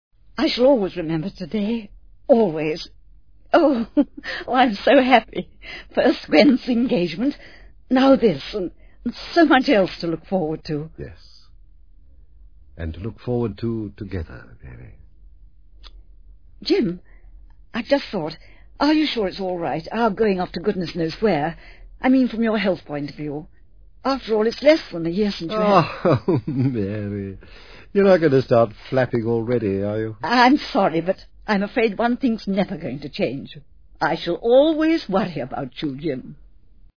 Programme Intro
short radio clip